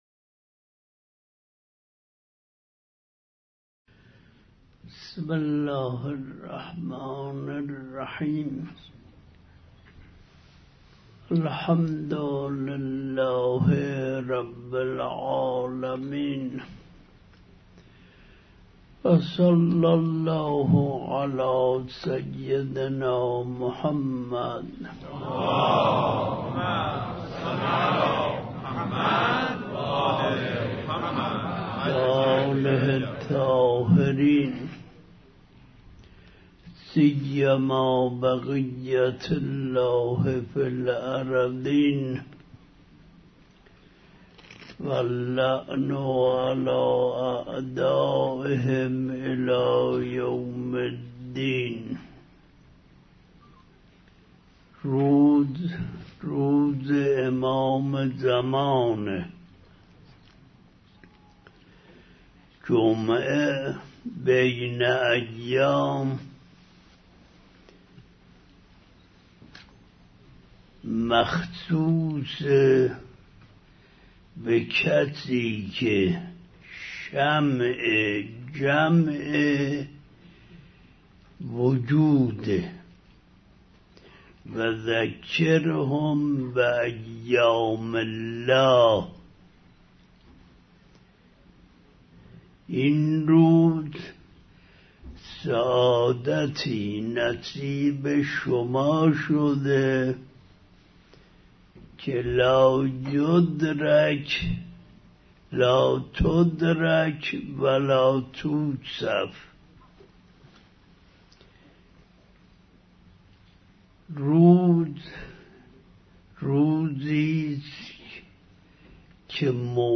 بیانات آیة الله العظمی وحید خراسانی مدظله در دیدار با خادمین فاطمیه1391 | سایت رسمی دفتر حضرت آيت الله العظمى وحيد خراسانى